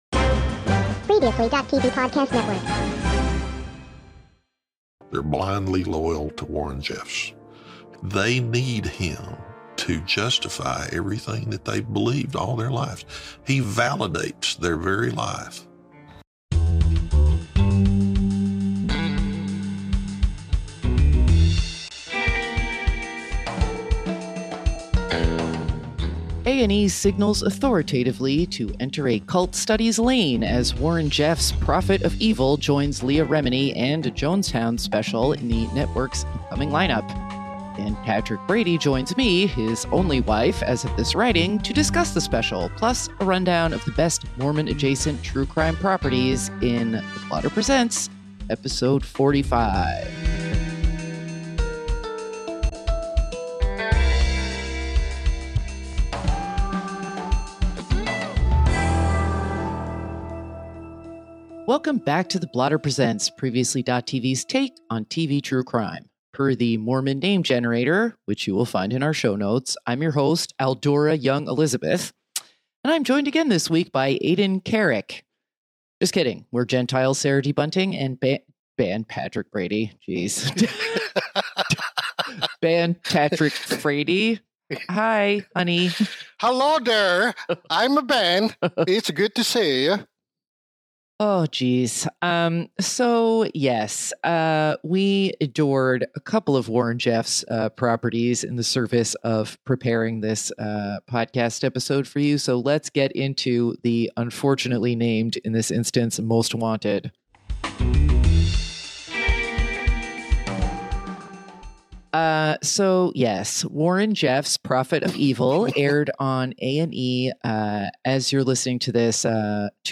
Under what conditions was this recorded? [Note: Today's episode contains crackling that may annoy you. We apologize for the inconvenience and are working on ironing out the issue!]